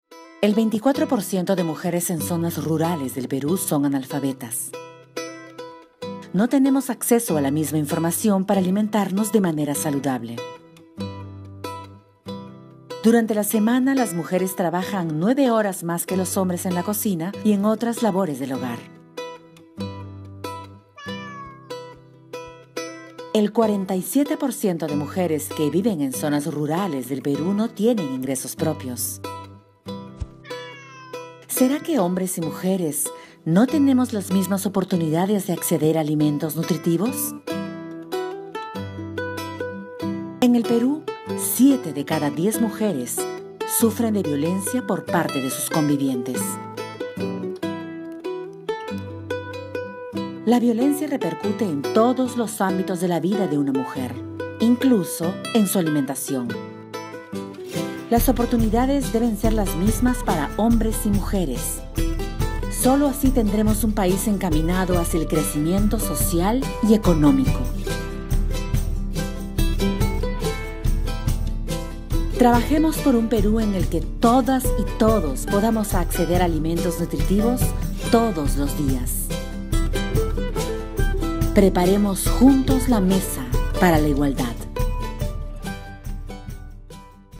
Espanhol - América Latina Neutro
Voz Padrão - Grave 01:27